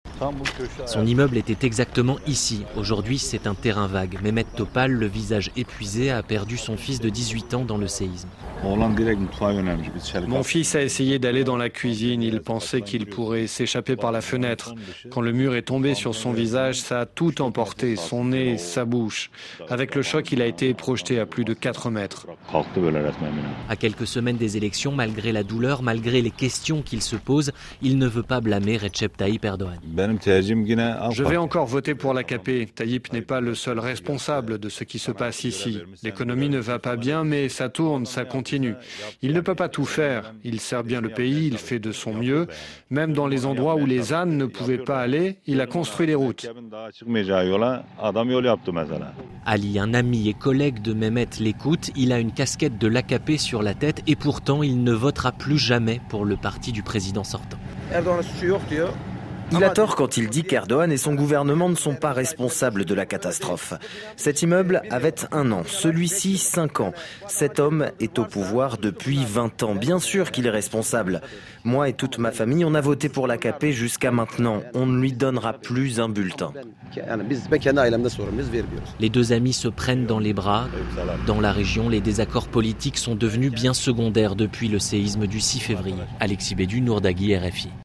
Les victimes divisées sur la gestion d’Erdogan. Reportage à Nurdagi à quelques kilomètres de l’épicentre.